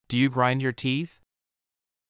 ﾄﾞｩ ﾕｰ ｸﾞﾗｲﾝﾄﾞ ﾕｱ ﾃｨｰｽ